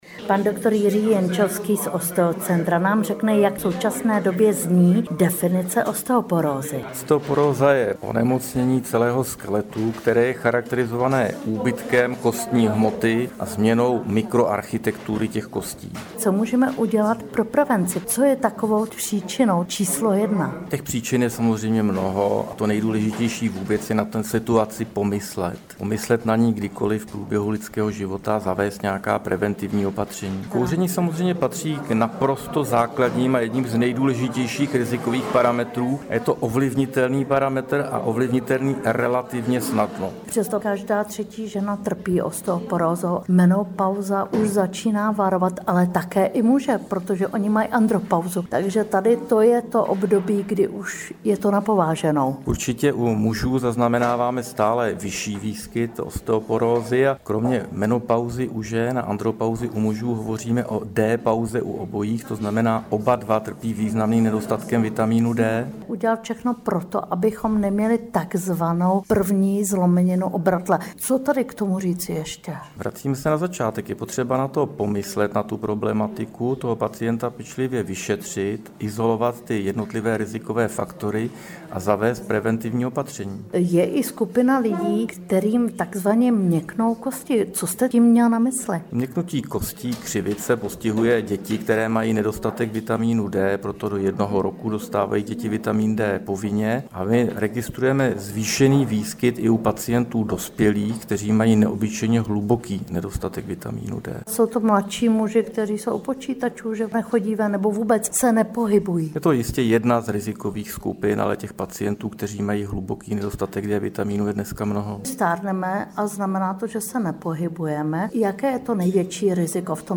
AUDIO rozhovor: Co jsme se ještě zjistili o osteoporóze?